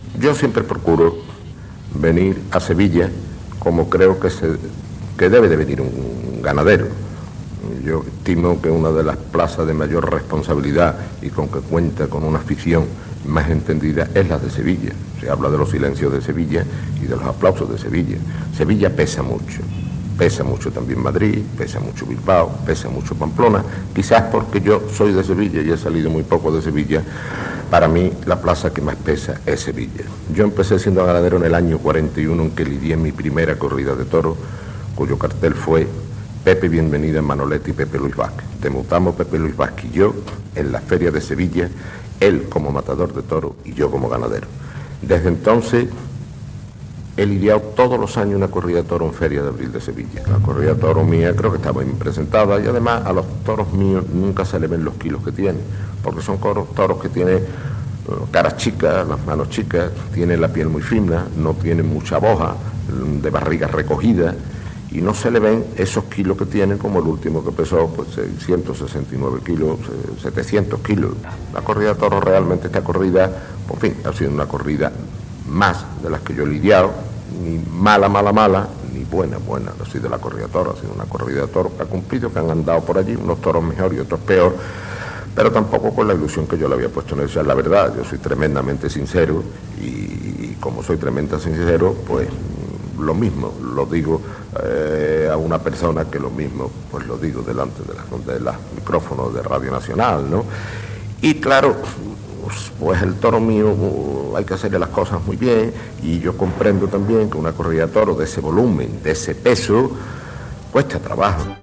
Entrevista al ramader Eduardo Miura sobre el compromís de portar braus a la Feria de Abril de Sevilla, després de l'última correguda de braus lidiada en aquella fira taurina